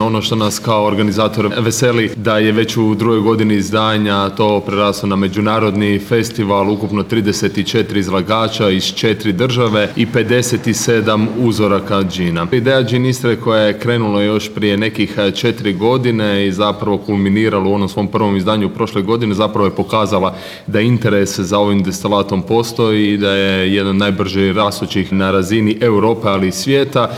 ROVINJ - Drugi GinIstra Festival sve je bliže pa je tim povodom ovog utorka u Rovinju održana konferencija za medije na kojoj je predstavljeno ovogodišnje izdanje Festivala, a koje će se upravo u Rovinju, u Staroj tvornici duhana, održati ovog vikenda - u petak i subotu.